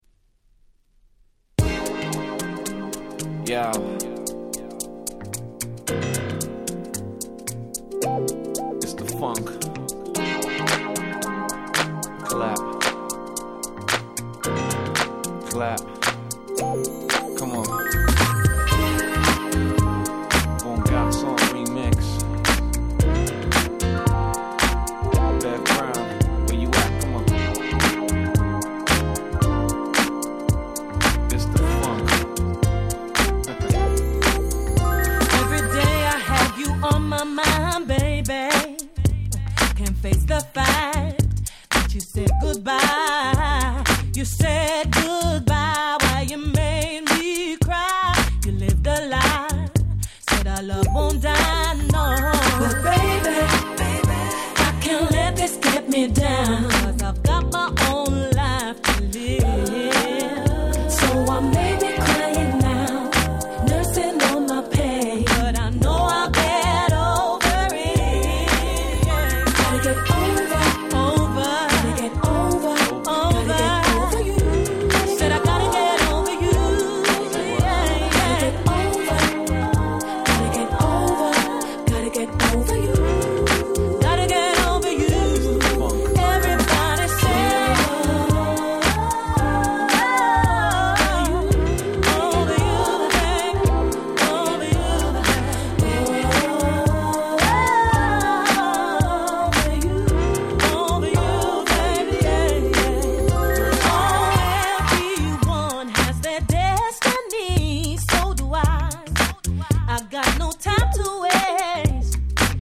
02' Nice UK R&B !!